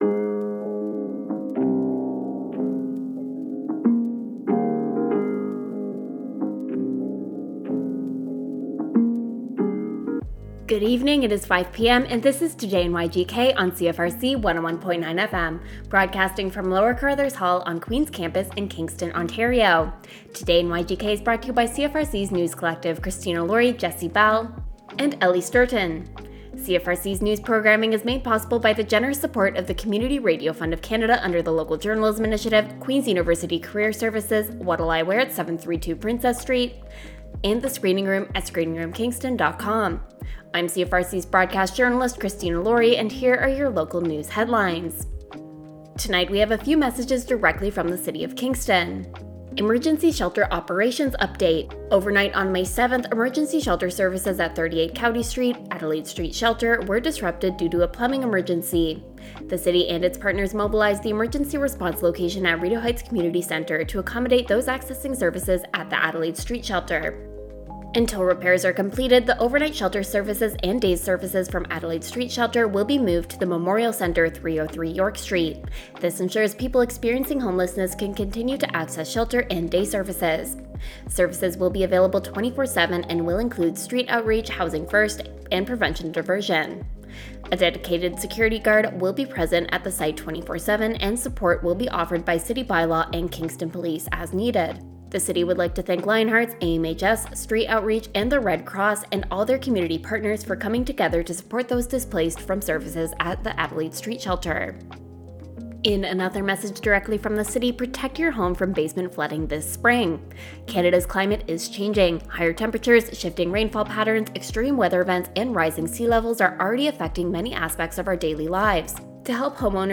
the latest in Limestone local news with CFRC’s News Team!